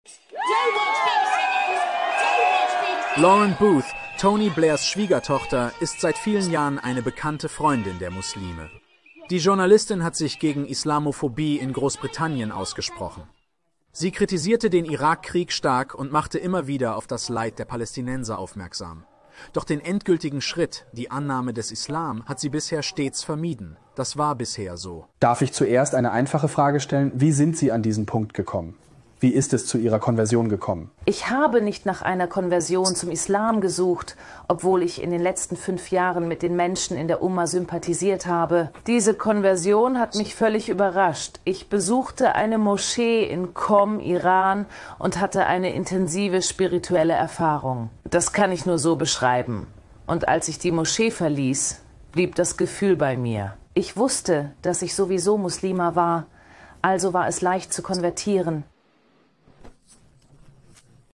Beschreibung: In diesem Video erzählt Lauren Booth, die Schwägerin von Tony Blair, ihre Geschichte zum Islam.